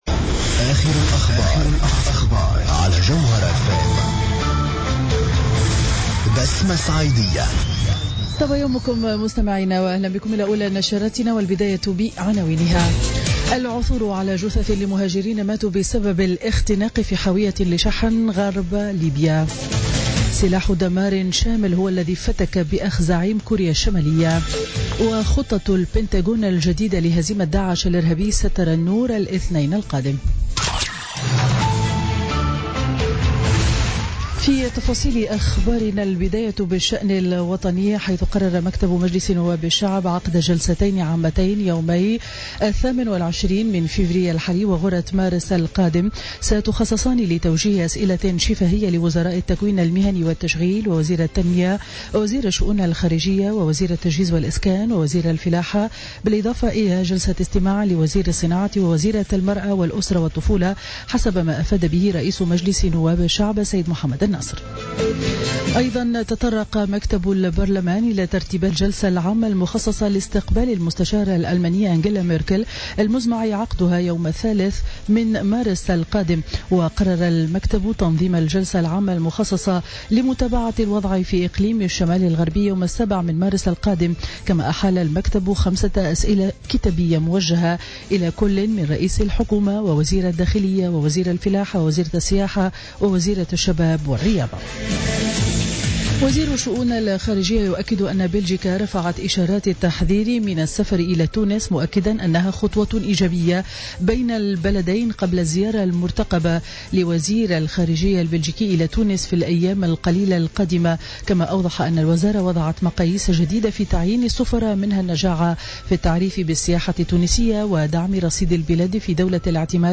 نشرة أخبار السابعة صباحا ليوم الجمعة 24 فيفري 2017